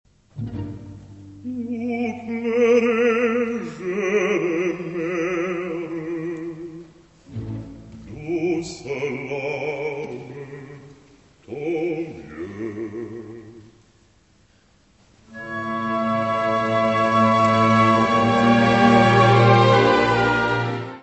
: stereo; 12 cm
Music Category/Genre:  Classical Music
& Air (Pére de Famille, Choeur) .